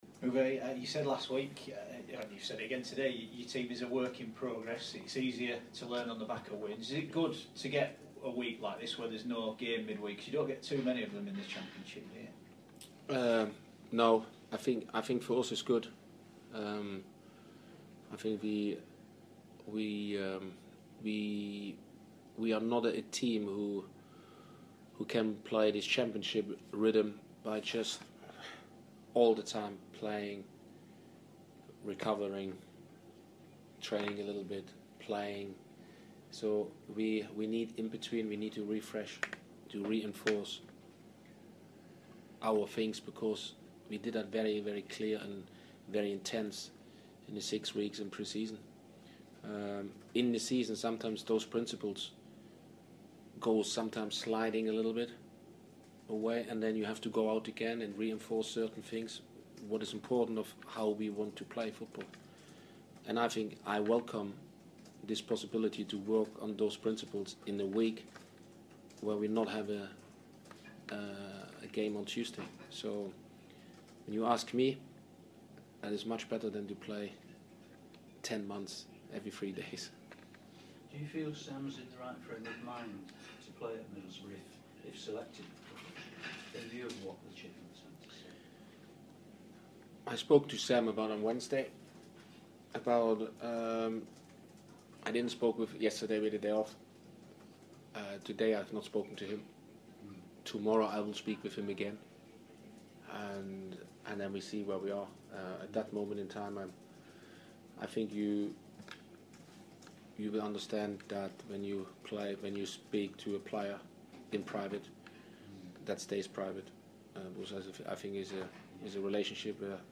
Leeds United head coach Uwe Rosler talks